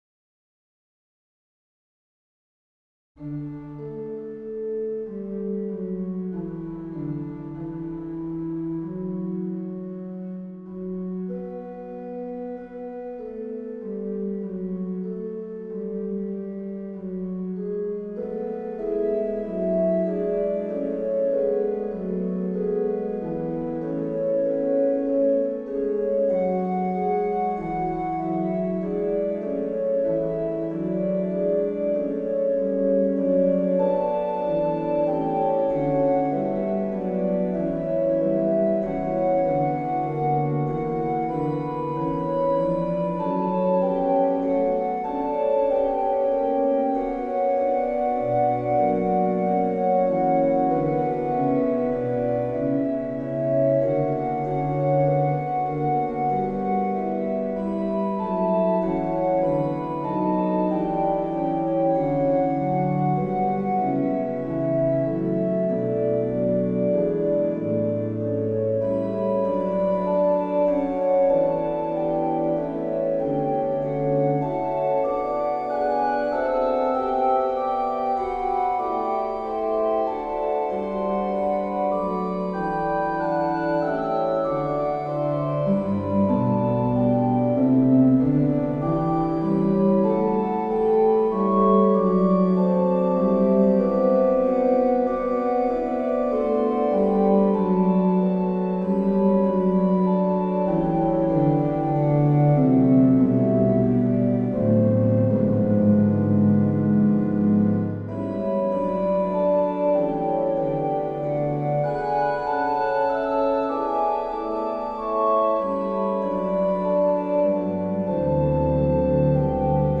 Organ   3:27 "Dans ta maison je suis heureux" (Fuga & Koraal)